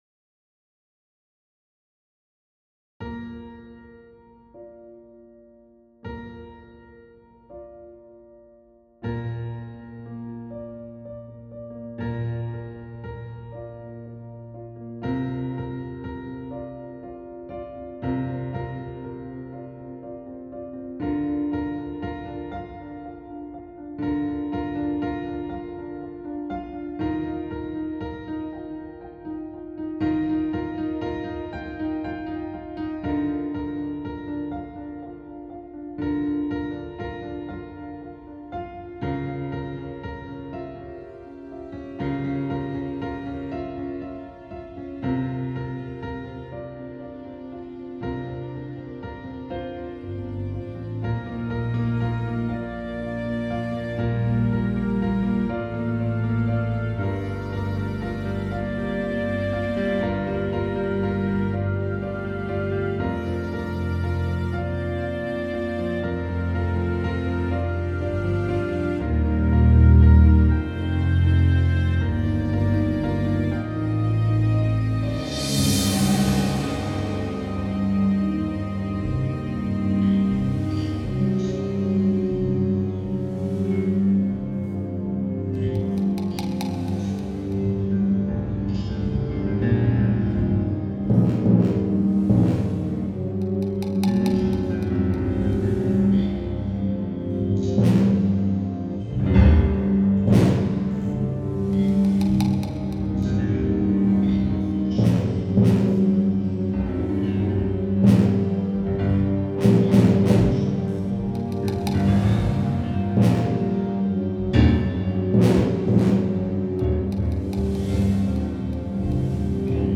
Compo 1: Très expressif, cinématique, j'aime bien les découpages par phases ( lévé, récupération-confusion cérébrale, reprise des esprits momentané, à nouveau chaos cérébral) très réaliste.
J'ai voulu dans la première partie alterner des parties de sommeil paisible avec des phases plus chaotiques, à l'aide de tensions musicales... Quand le réveil sonne (3x le triangle), j'ai voulu amener une sensation un peu "pataude"...
;) s'amplifie majestueusement, comme un rêve, une scène romantique au cinéma... belle tension harmonique obtenue par le mouvement systématique des notes...
Ah vwouch, ça devient louche et stressant.. peut être que le rêve tourne au cauchemar et que notre matinée difficile se passe au lit ?
Re-schwiiif de cymbale, comme les petites gouttes de pluie après la tempête. Petite embardée stressée du piano, le danger persiste.... dring dring, le réveil ??